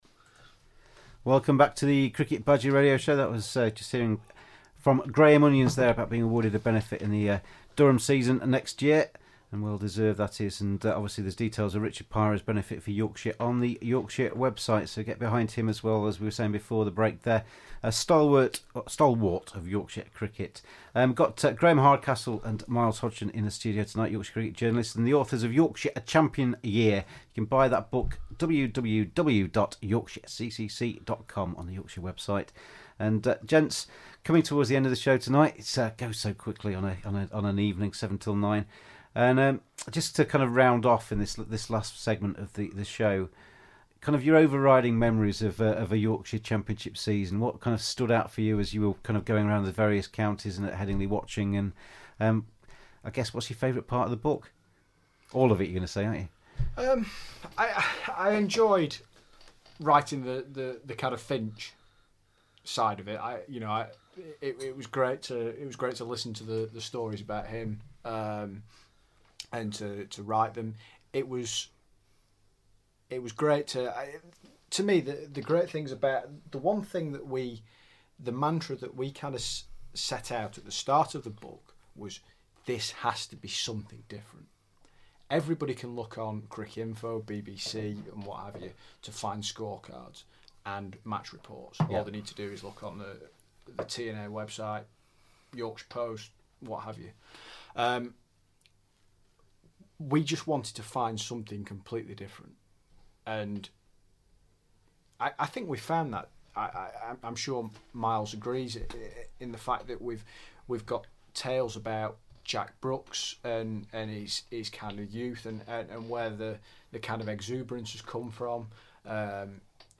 INTERVIEW: Yorkshire: A Champion County (Part Six)